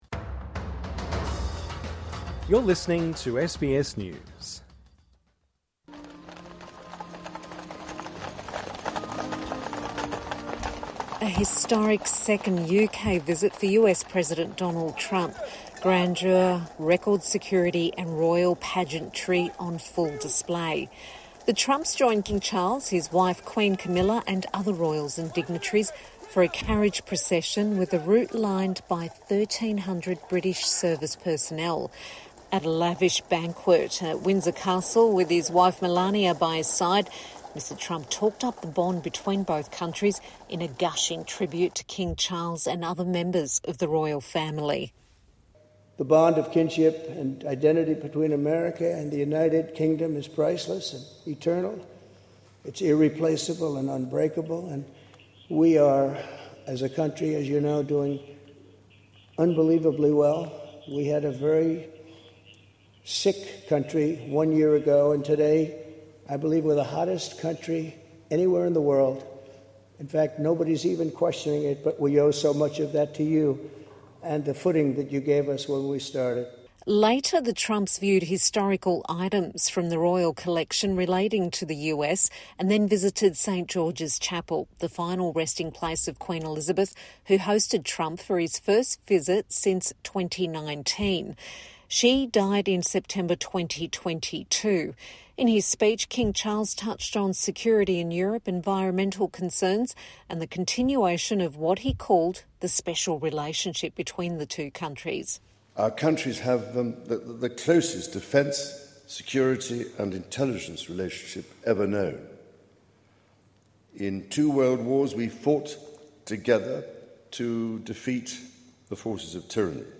TRANSCRIPT (Sound of horse and carriage) A historic second UK state visit for US President Donald Trump - grandeur, record security and royal pageantry on full display.